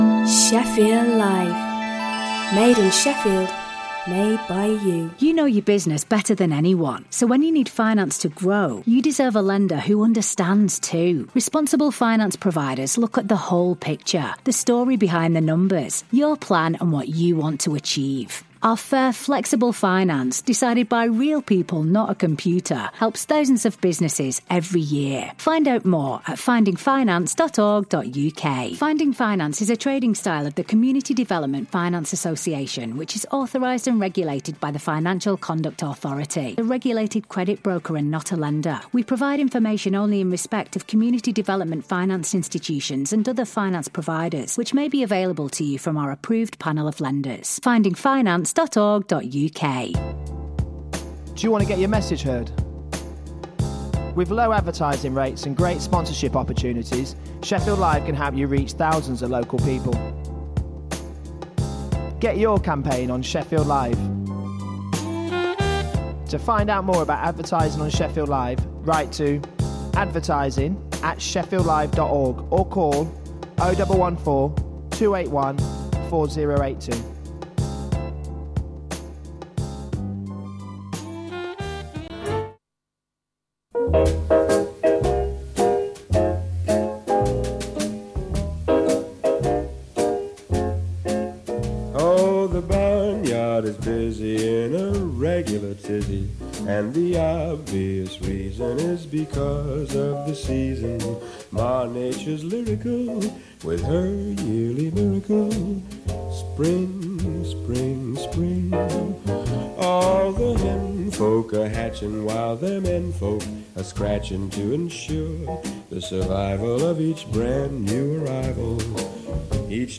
Film and theatre reviews plus swing classics.